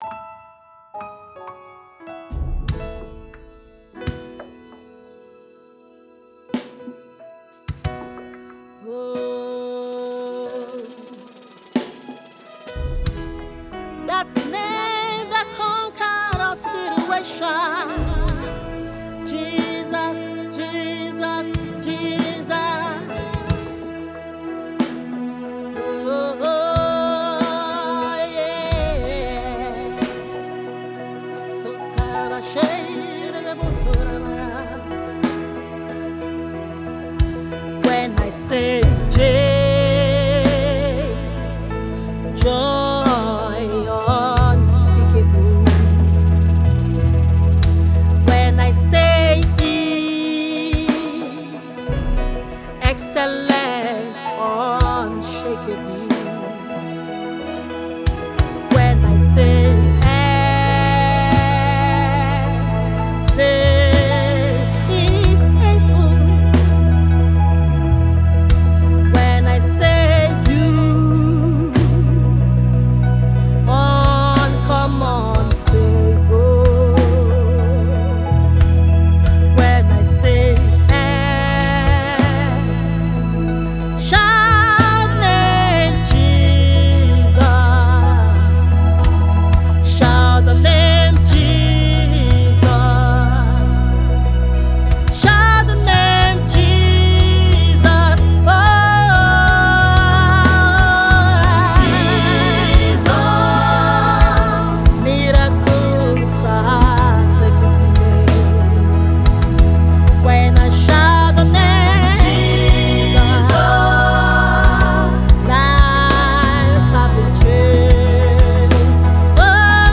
inspirational worship song